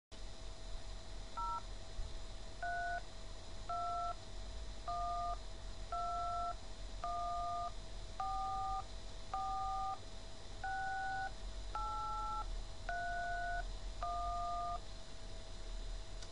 Ich kann mich nur noch daran erinnern, wie sich das Wählen auf der Telefontastatur angehört hat.